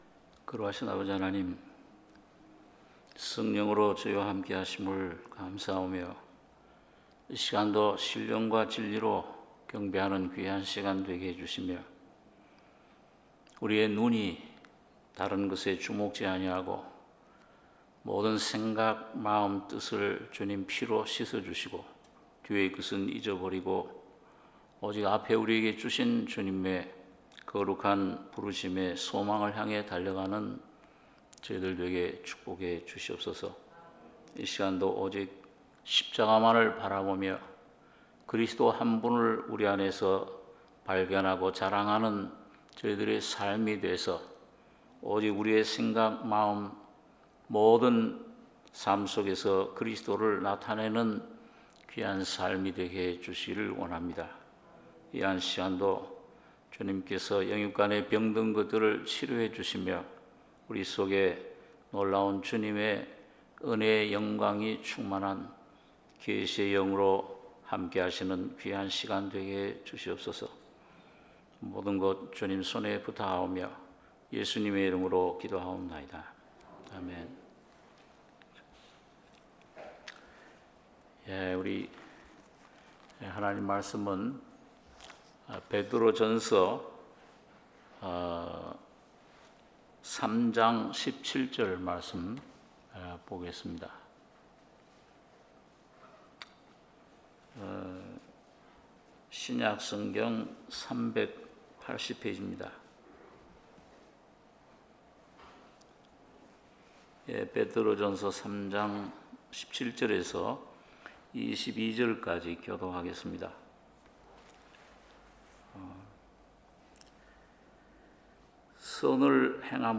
수요예배 베드로전서 3장 17~22절